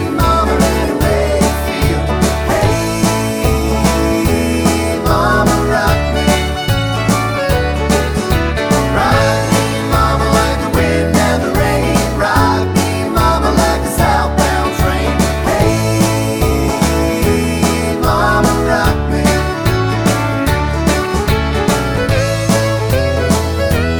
no Backing Vocals Country (Male) 4:32 Buy £1.50